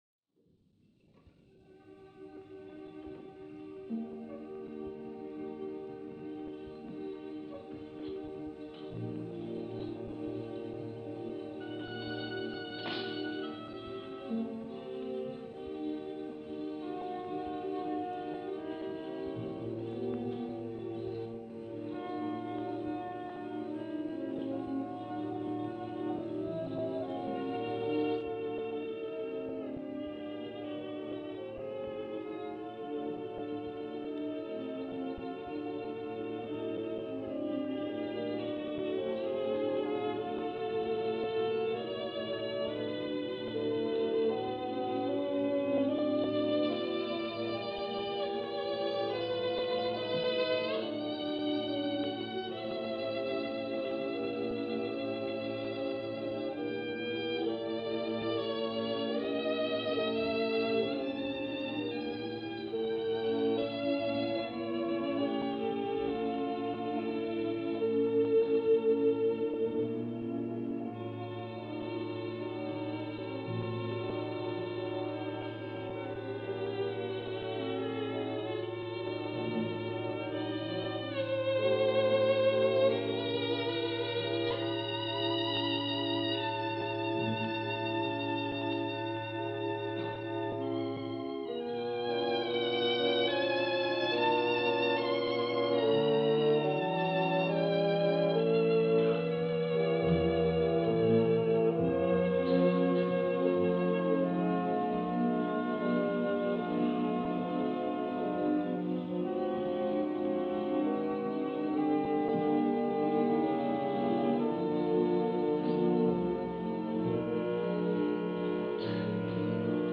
1949 Besançon Festival